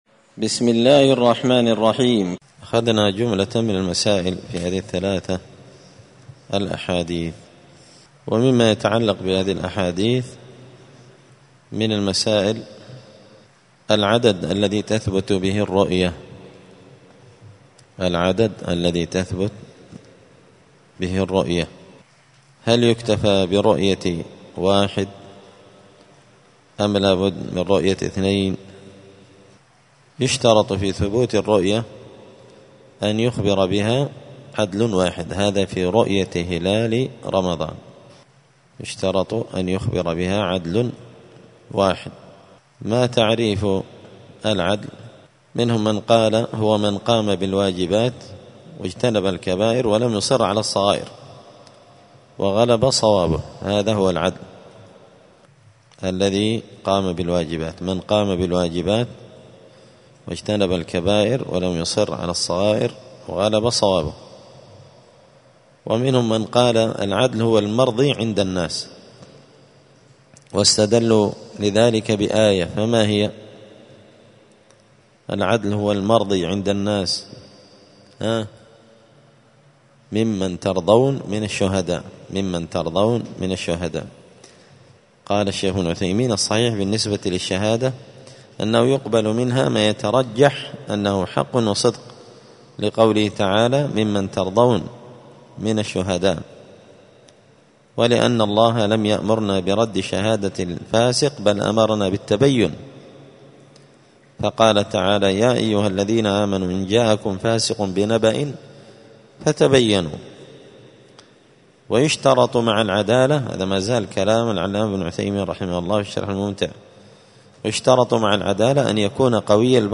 دار الحديث السلفية بمسجد الفرقان بقشن المهرة اليمن
*الدرس الرابع (4) {بماذا تثبت رؤية هلال رمضان…}*